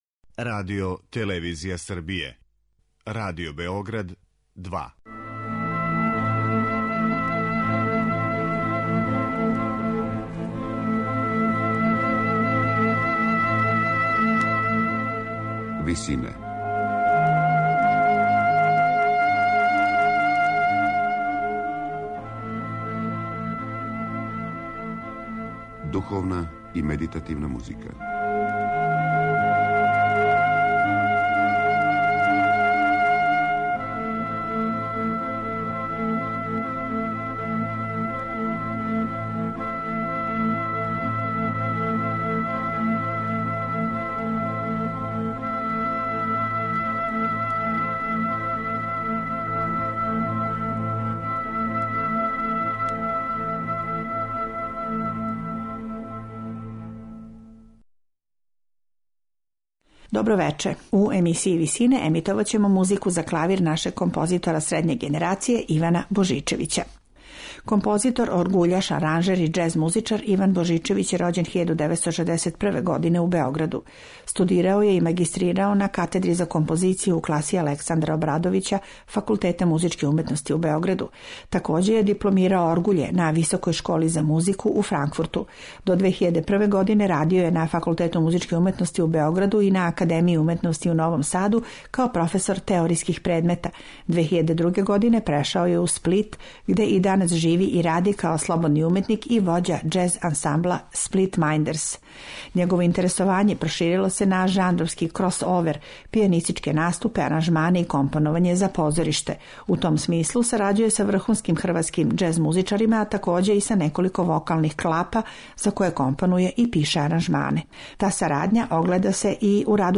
дела за клавир
медитативне и духовне композиције